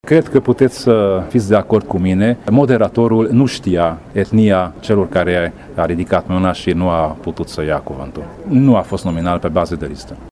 Preşedintele Consiliului Judeţean Covasna, Tamas Sandor a negat aceste acuzaţii, spunând că nu a fost un gest intenţionat: